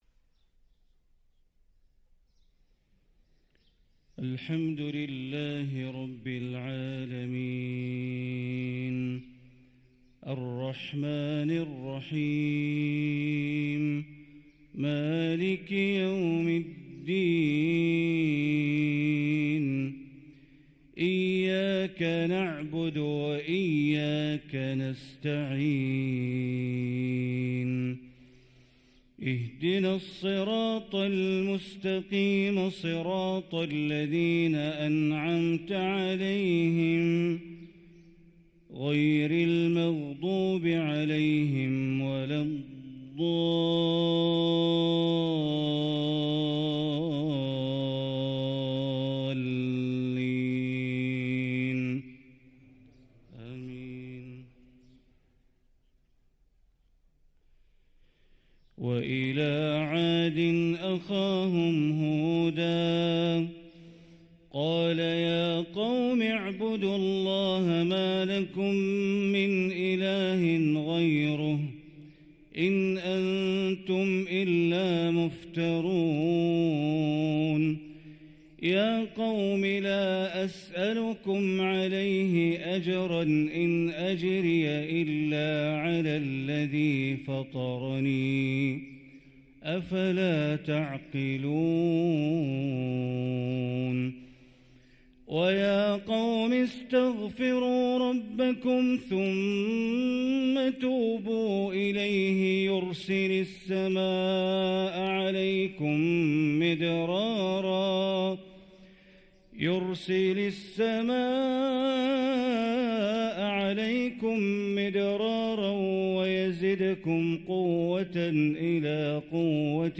صلاة الفجر للقارئ بندر بليلة 14 صفر 1445 هـ
تِلَاوَات الْحَرَمَيْن .